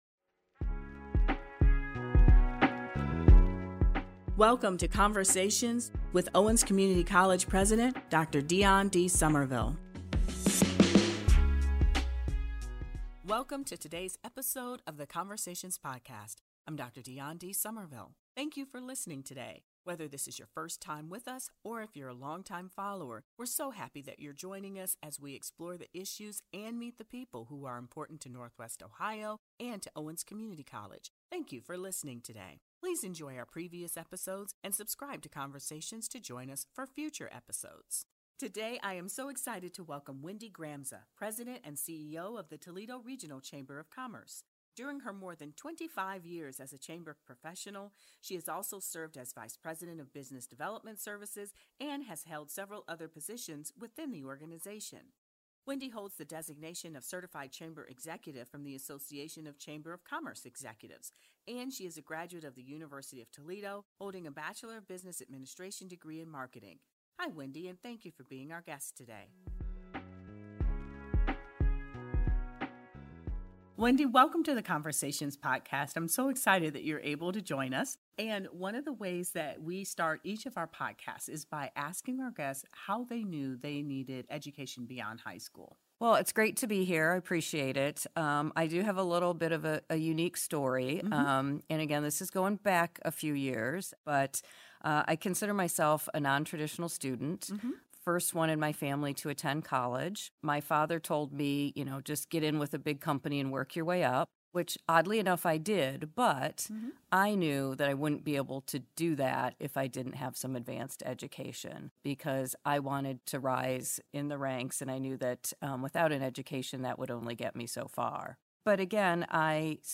is an award-winning podcast featuring a variety of leaders in education, public service, business and civic life.